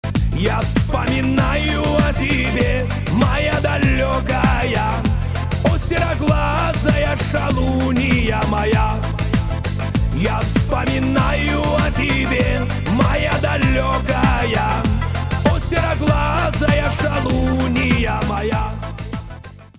русский шансон